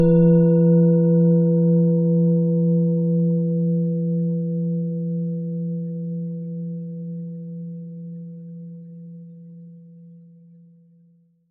Planetenton
Um den Original-Klang genau dieser Schale zu hören, lassen Sie bitte den hinterlegten Sound abspielen.
SchalenformBihar
MaterialBronze